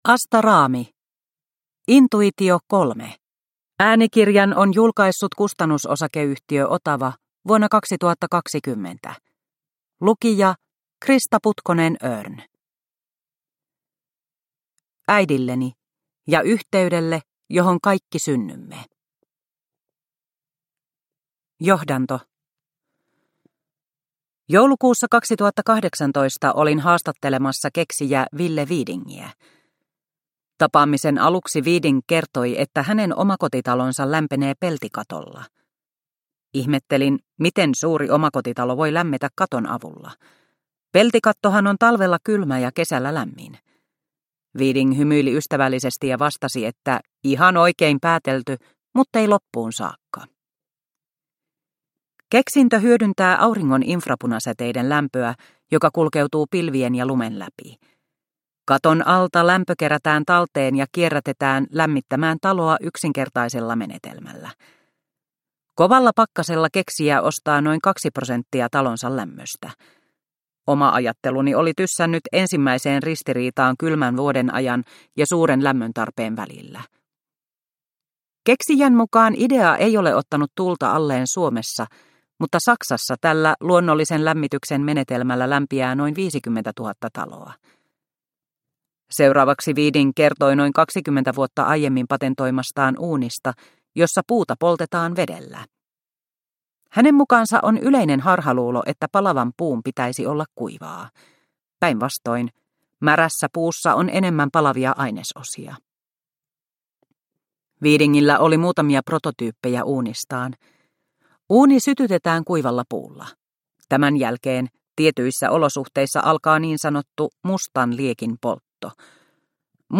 Intuitio3 – Ljudbok – Laddas ner